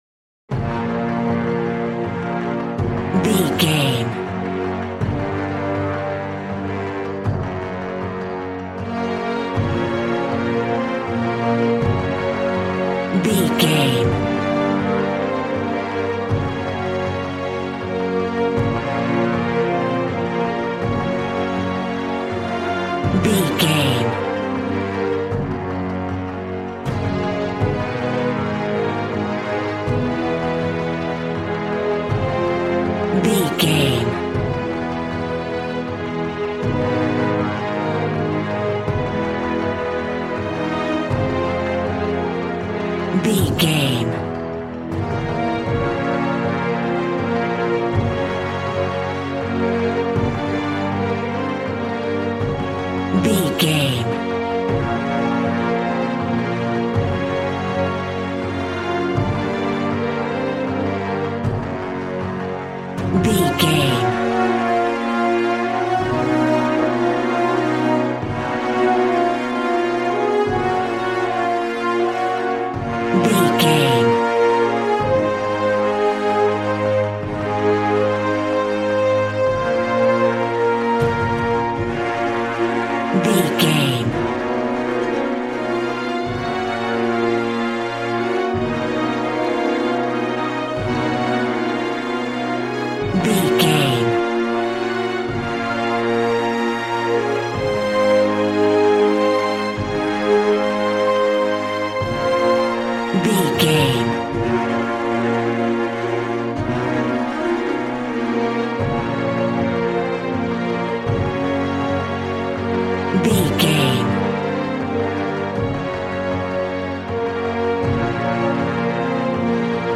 Aeolian/Minor
B♭
dramatic
epic
strings
violin
brass